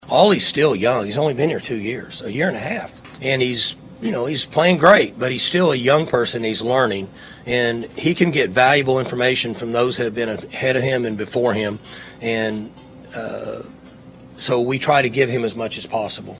Oklahoma State head football coach Mike Gundy spoke with the media on Monday ahead of the Final Bedlam on the books on Saturday afternoon in Stillwater.